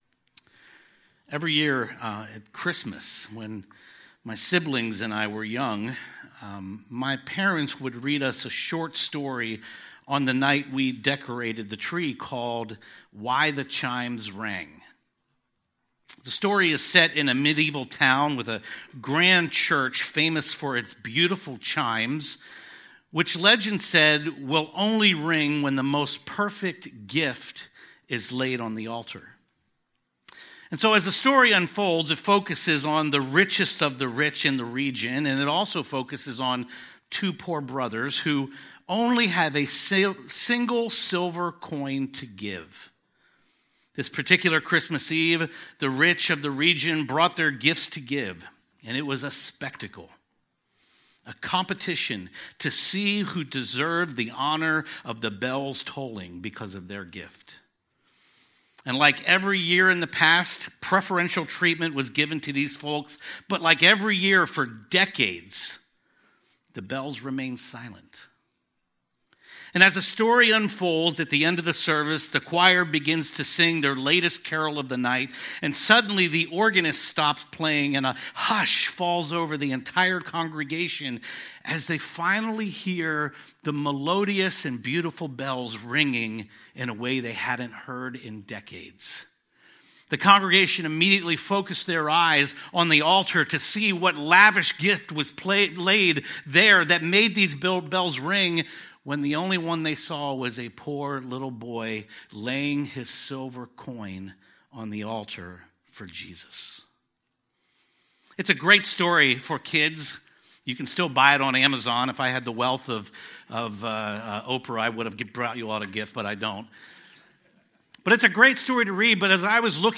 A sermon on James 2:1-7